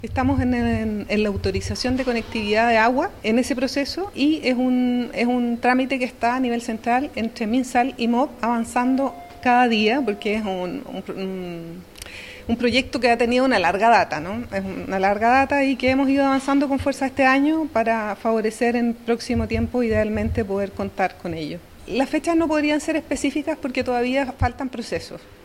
Por su parte, la seremi de Salud, Ivone Arre, indicó que están esperando autorizaciones para la conectividad de agua, trámite que se está efectuando en el nivel central entre el Minsal y el Ministerio de Obras Públicas.